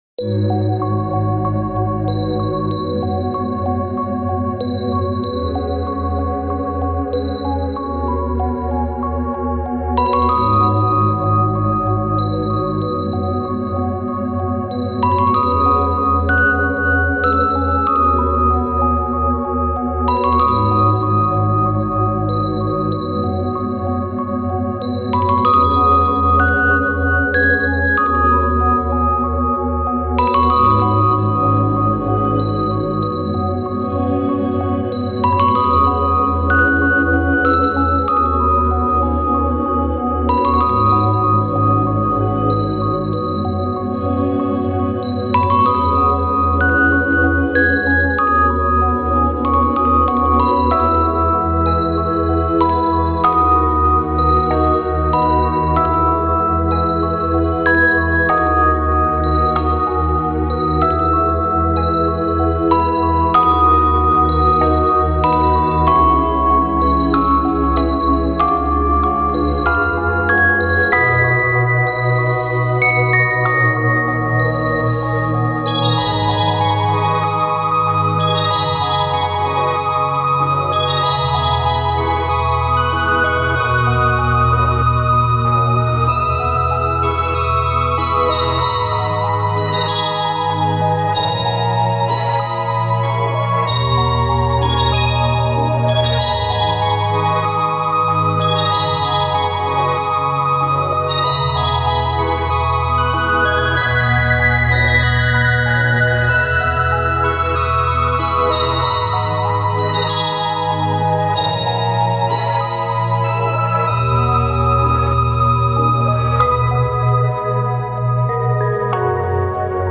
フリーBGM イベントシーン 切ない・悲しい
フェードアウト版のmp3を、こちらのページにて無料で配布しています。